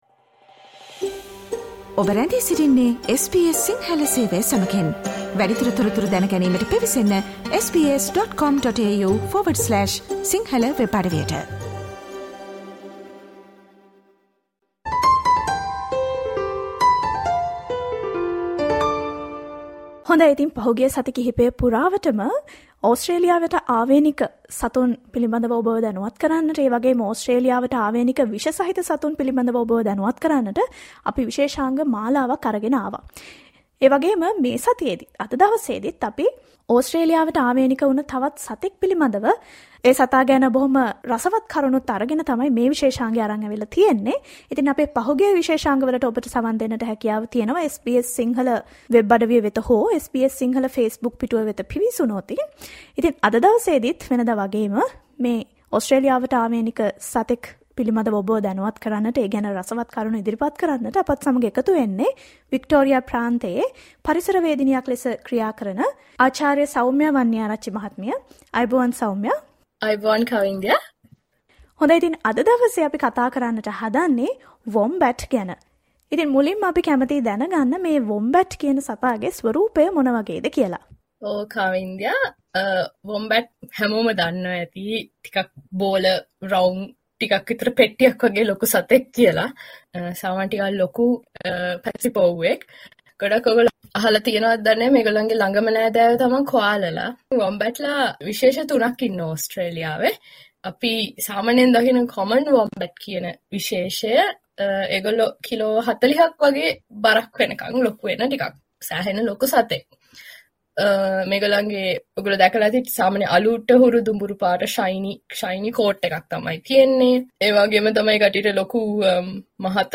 ඔස්ට්‍රේලියාව යනු ජෛව විවිදත්වයෙන් පිරුණු බොහොම සුන්දර රටක්. මෙම රටට ම පමණක් ආවේණික වුණු සත්ත්වයන් සහ ශාක රැසකට ඔස්ට්‍රේලියාව නිවහන වේ. මේ හේතුවෙන් ඕස්ට්‍රේලියාවෙන් හමුවන ඕස්ට්‍රේලියාවට ආවේනික 'වොම්බැට්' සතුන් පිළිබඳව SBS සිංහල සේවය සිදු කල සාකච්ඡාව